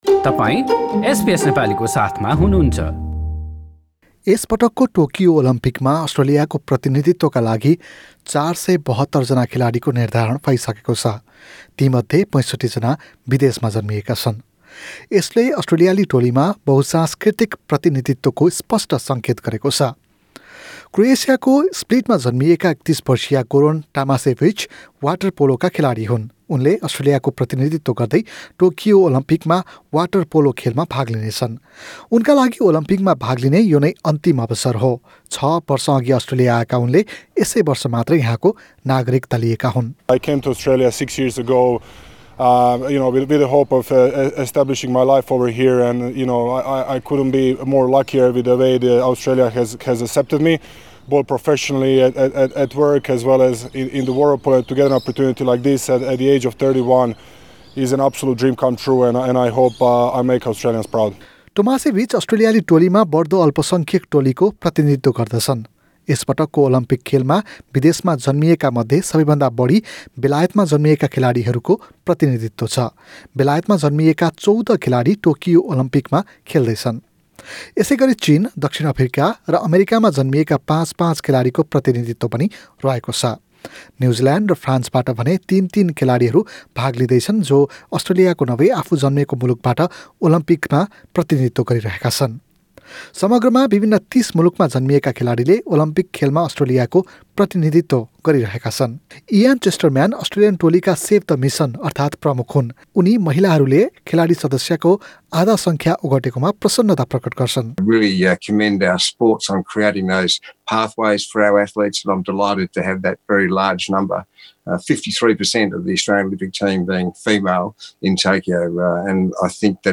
२३ जुलाई बाट सुरु हुने टोकियो ओलम्पिक खेलमा अस्ट्रेलियाबाट उल्लेखनीय रूपमा महिला एवम् आदिवासी खेलाडीहरूको प्रतिनिधित्व रहने भएको छ। एक रिपोर्ट।